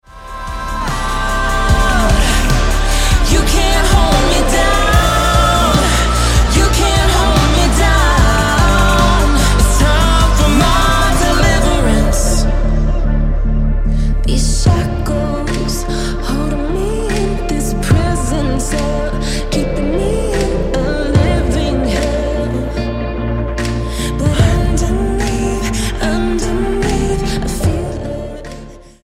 alternative pop duo
alternative rock/Americana duo
Style: Pop